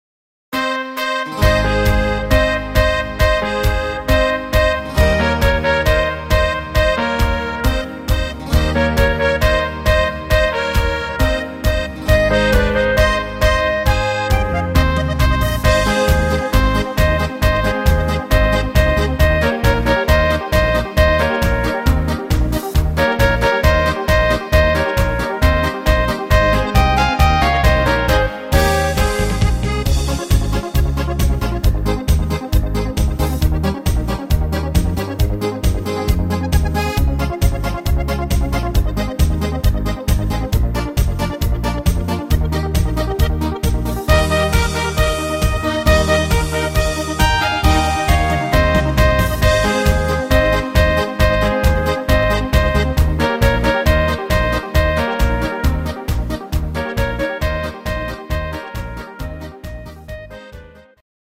Rhythmus  Beat Marsch
Art  Volkstümlich, Deutsch